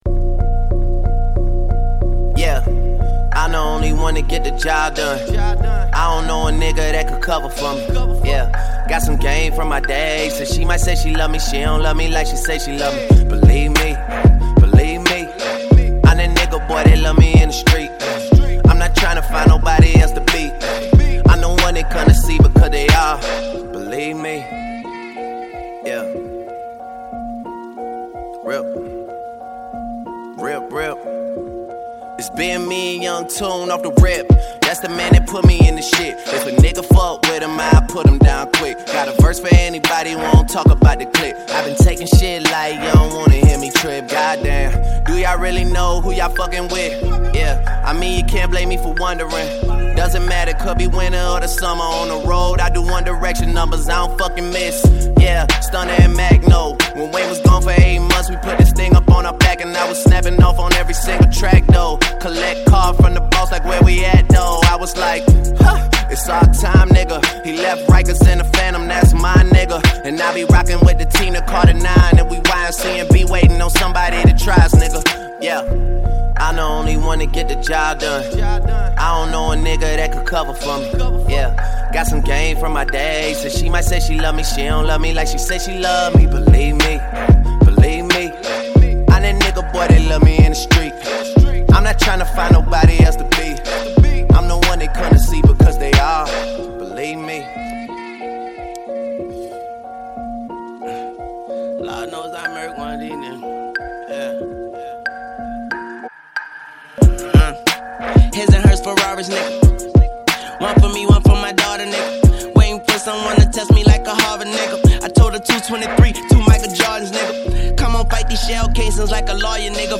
Жанр: Hip-Hop